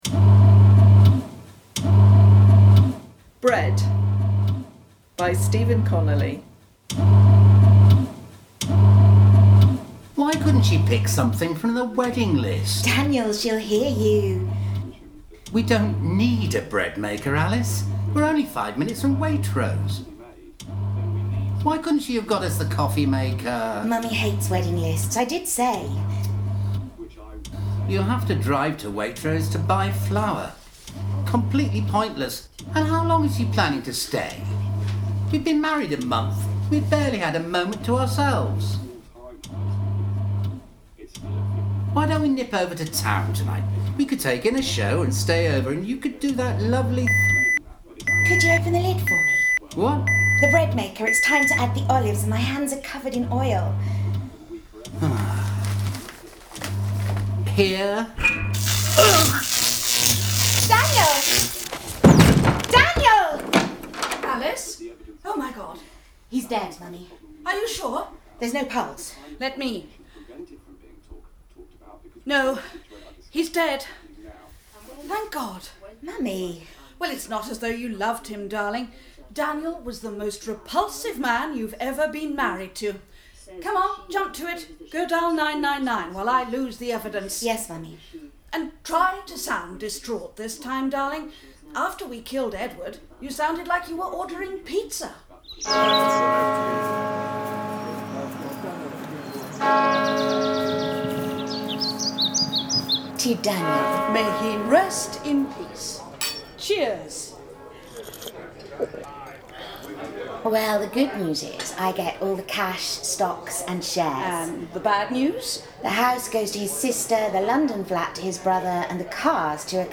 Bread – A Dark Comedy
It was recorded in Siddington Village Hall on August 20th in an internal space with reasonable acoustics (a store cupboard with a thick carpet) plus some scenes recorded outside. It was my first attempt to record using a Zoom H1 and I am very pleased with the results, despite a considerable breeze there was almost no wind noise on the recording.